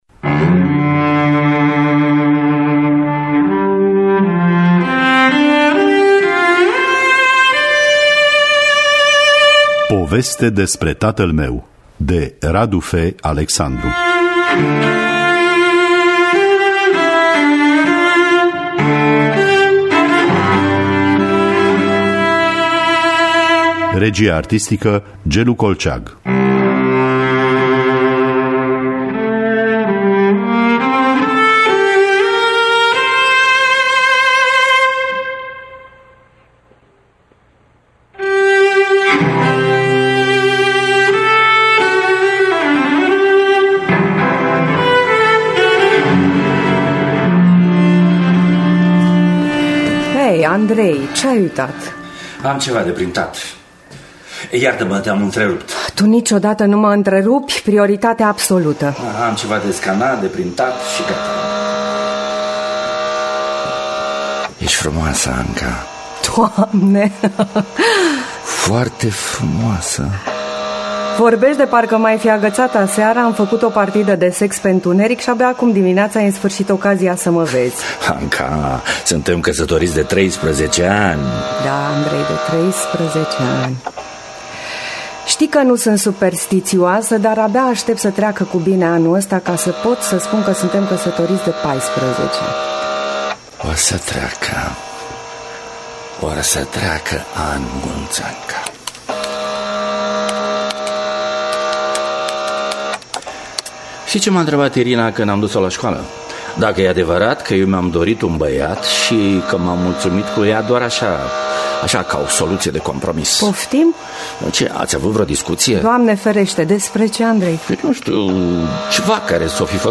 Poveste despre tatăl meu de Radu F. Alexandru – Teatru Radiofonic Online